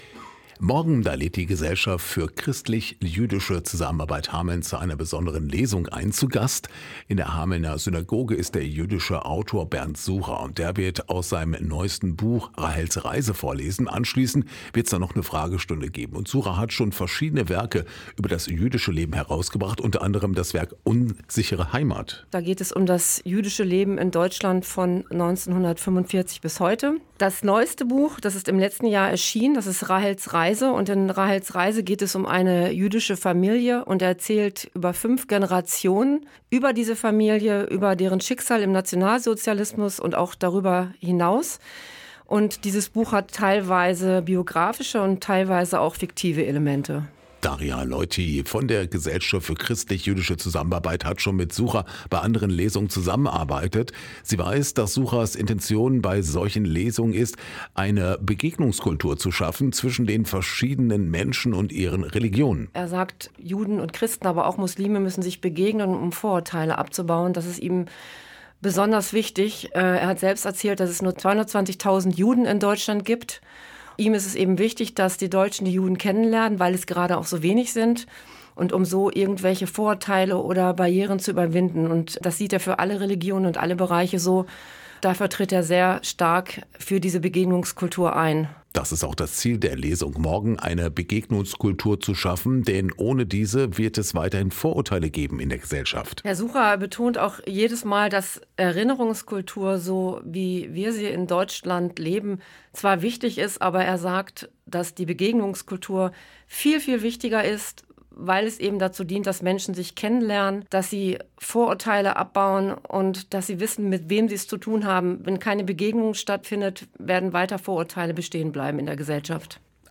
Hameln: Lesung des Buches „Rahels Reise“
hameln-lesung-des-buches-rahels-reise.mp3